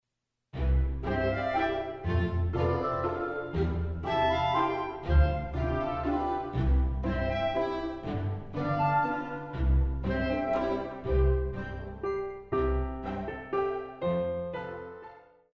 古典
鋼琴
管弦樂團
鋼琴曲,演奏曲
獨奏與伴奏
有主奏
有節拍器